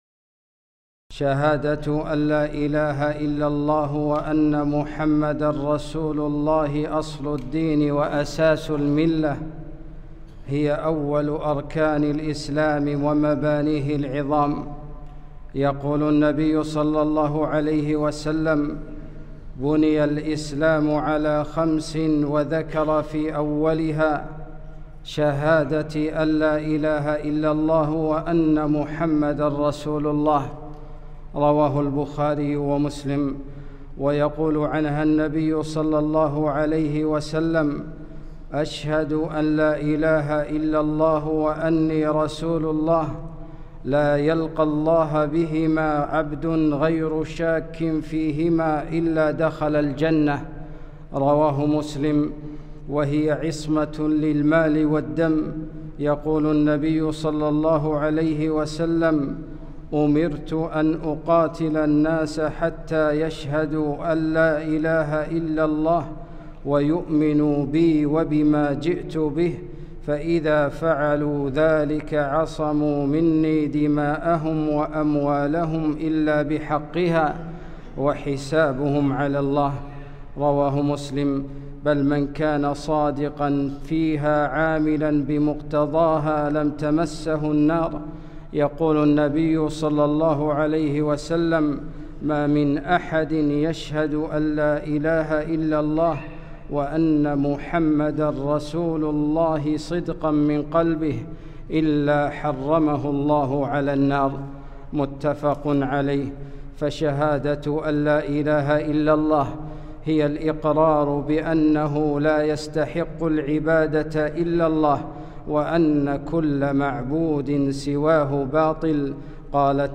خطبة - الشهادتان